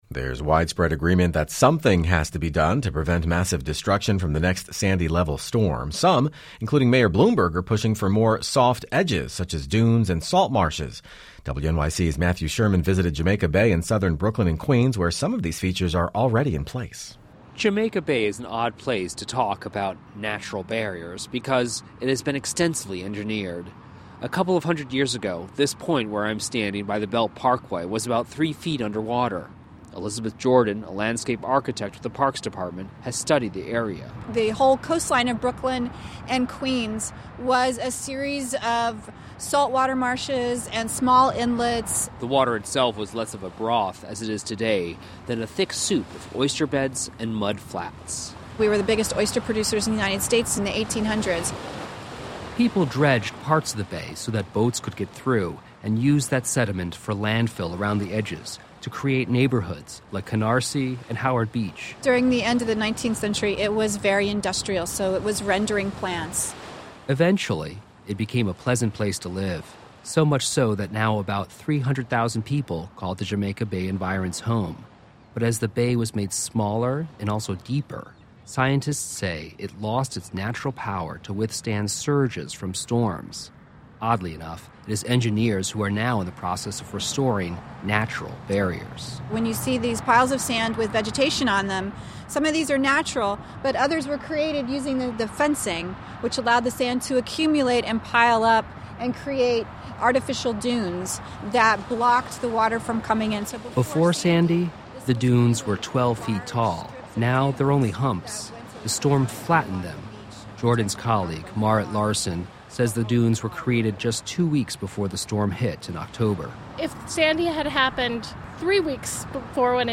WNYC Radio